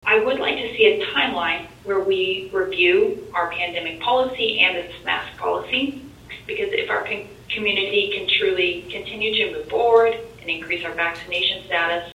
The board held a special meeting Wednesday night to adopt the plan before the start of classes.
Board Member Katrina Lewison, calling into the meeting, says she doesn’t see a school year starting without masks until those under 12 can get vaccinated.